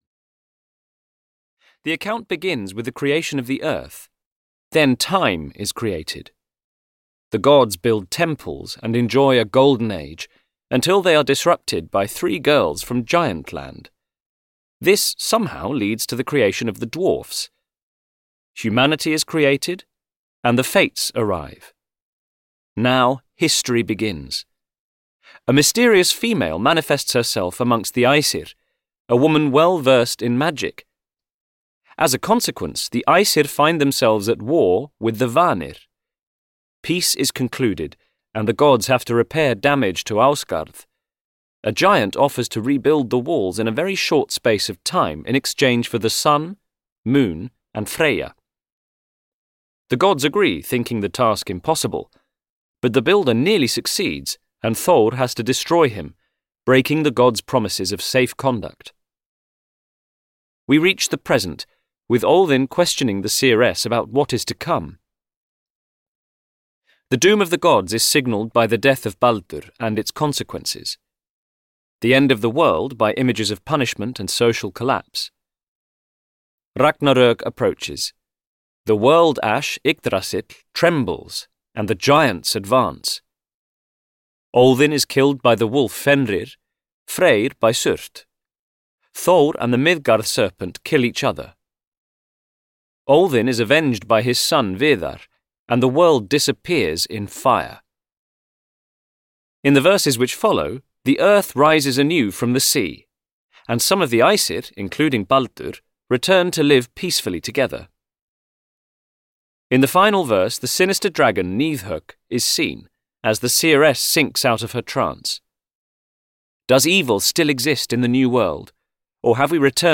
The Poetic Edda (EN) audiokniha
Ukázka z knihy